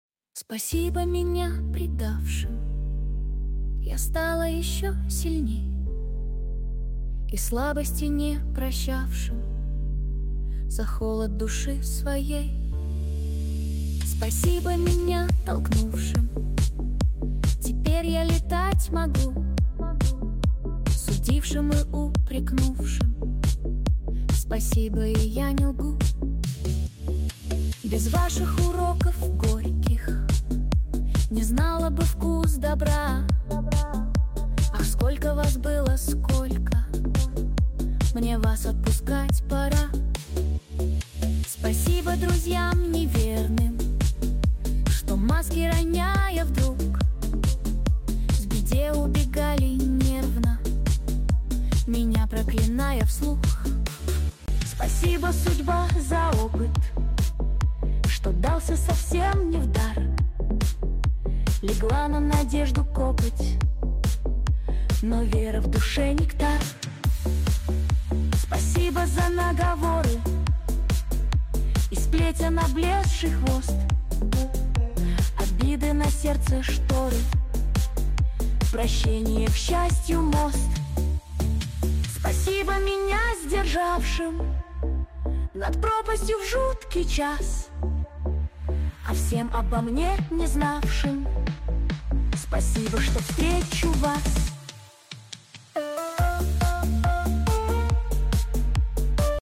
песня создана с помощью нейросети!